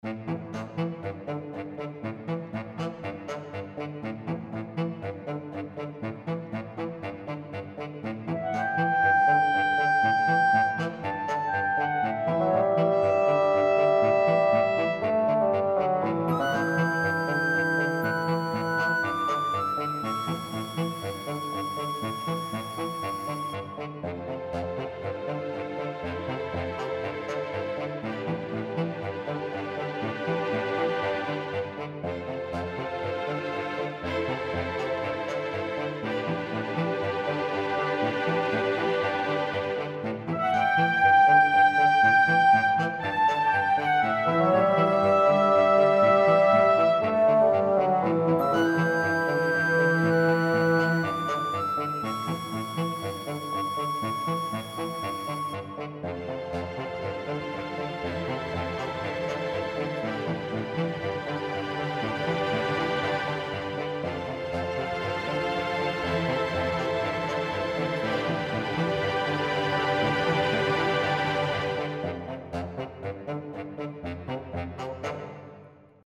• Music is loop-able, but also has an ending